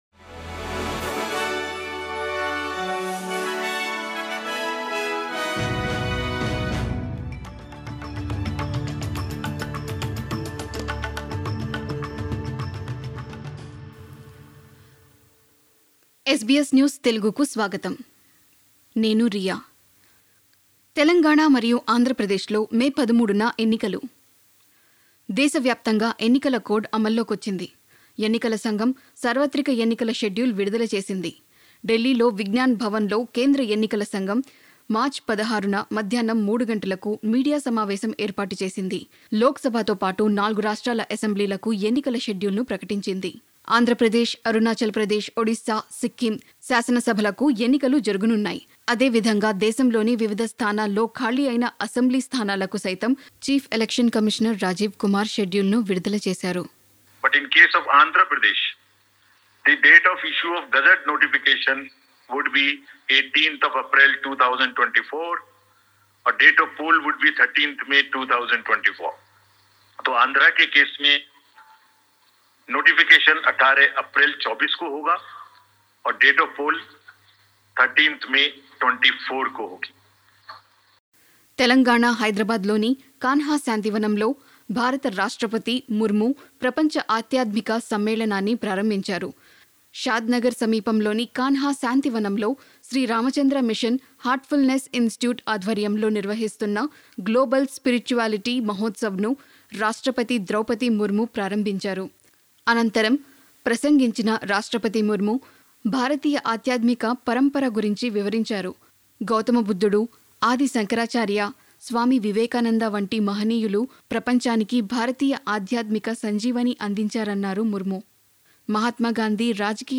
SBS తెలుగు 18/03/24 వార్తలు : తెలంగాణ మరియు ఆంధ్రప్రదేశ్ ఎన్నికలు!!